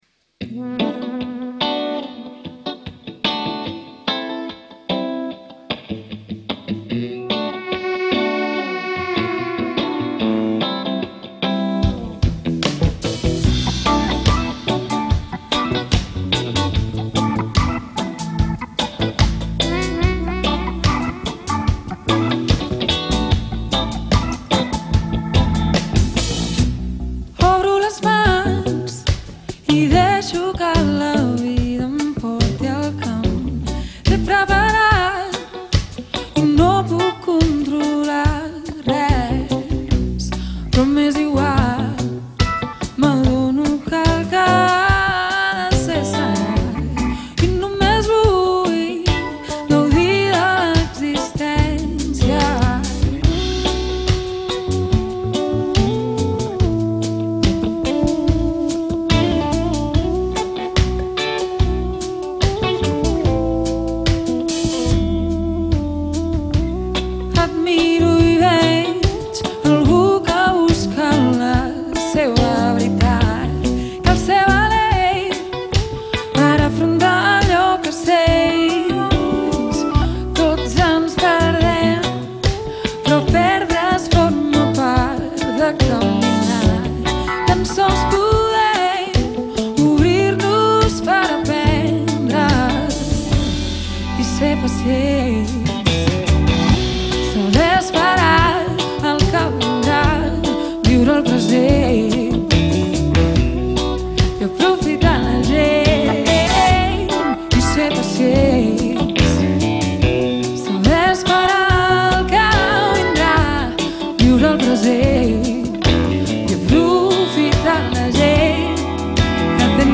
Catalan singer/composer/musician